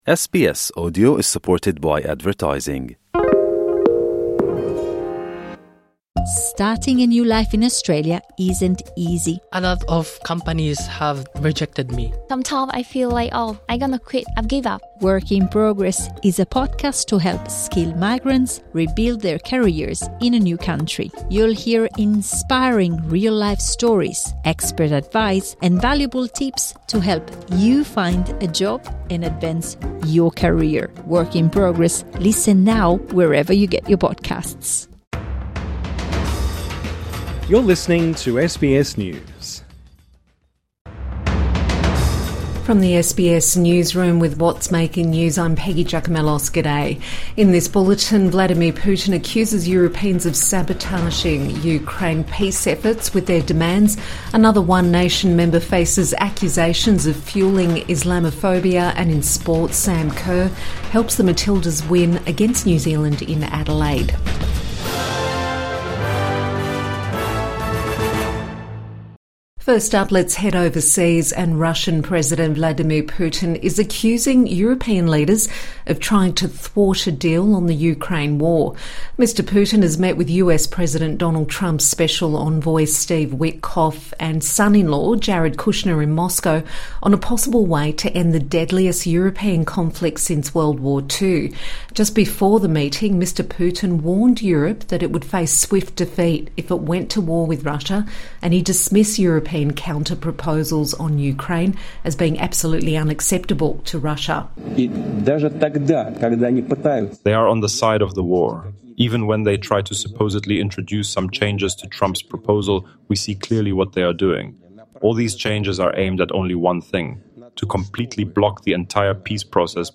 Putin accuses Europeans of sabotaging Ukraine peace efforts | Morning Bulletin 3 December 2025